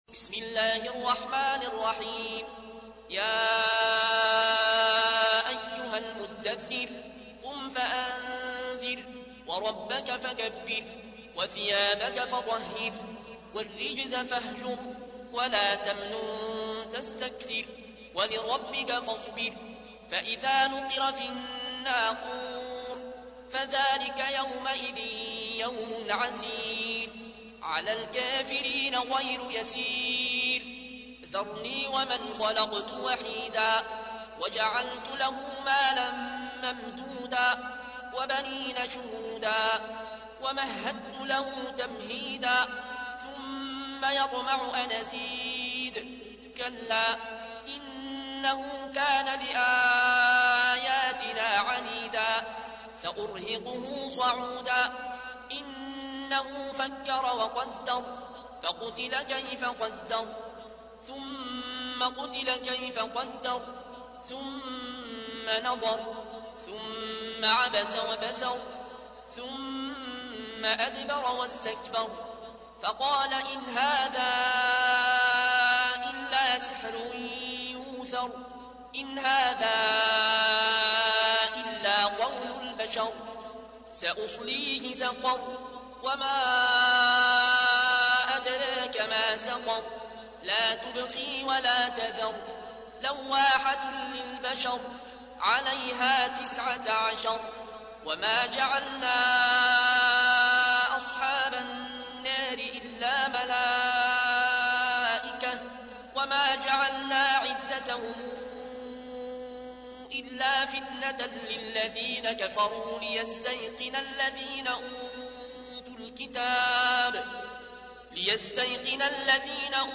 تحميل : 74. سورة المدثر / القارئ العيون الكوشي / القرآن الكريم / موقع يا حسين